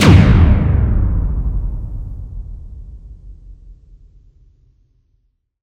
my custom boom i think im gonna use it for some big blast cannon Very Happy
i like the machine gun. but the explosion has to be WAY shortened
Erm, I have to say, the only really usable one is gun burst 3, but all of them, it included, are too echoy and distant, if you get what I'm saying.
ux2boom_878.wav